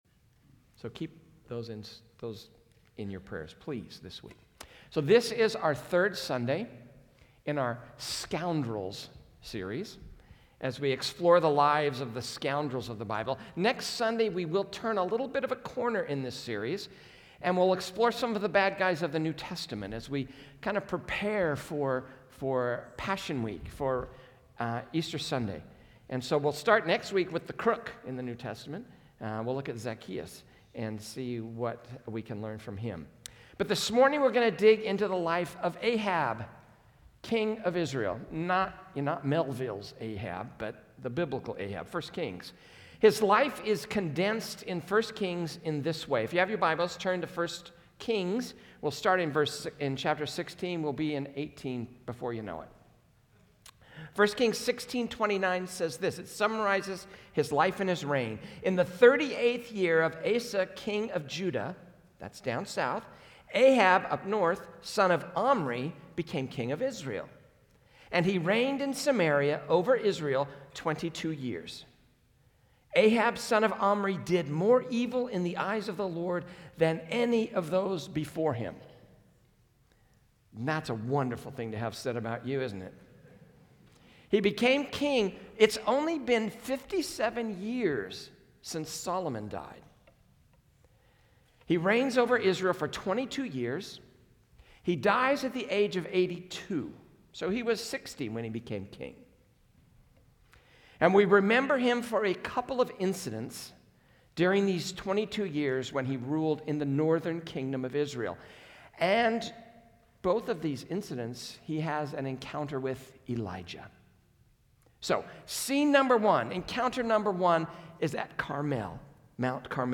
PCC Sermons